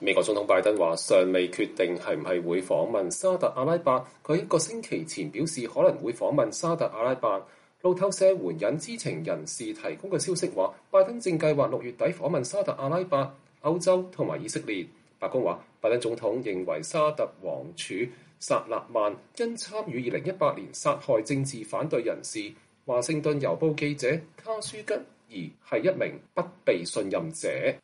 美國總統拜登在洛杉磯搭乘“空軍一號”專機前對記者發表講話（2022年6月11日）